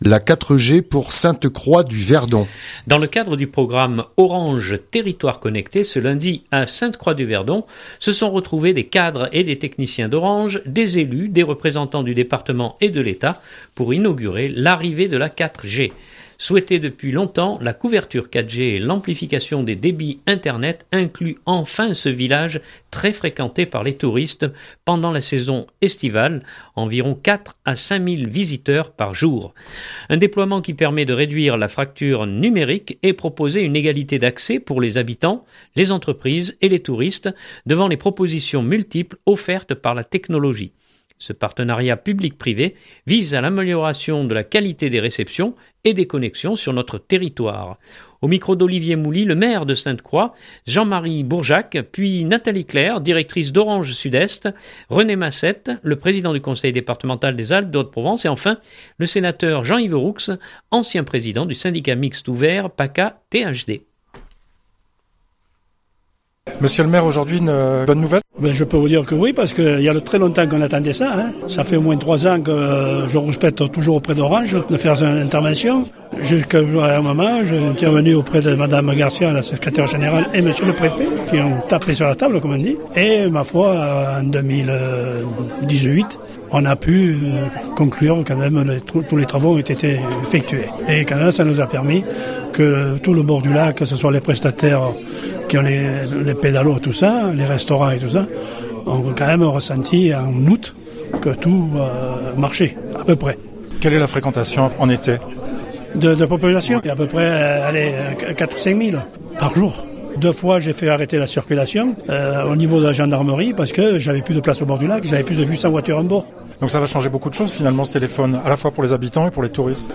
2018-12-11-Reportage-La 4g Pour Sainte-Croix-Du-Verdon.mp3 (8.22 Mo)